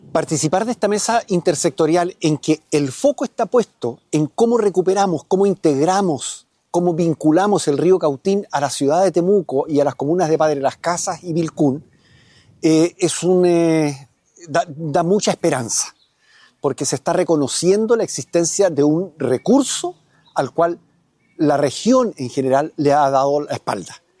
La situación del cuerpo de agua fue analizada en una reunión convocada por el Gobierno Regional de La Araucanía, en la que se revisaron los avances de cumplimiento de los compromisos de cada institución y del proceso de participación ciudadana, además de coordinar acciones para informar a la comunidad, tal como lo detalló el gobernador, René Saffirio.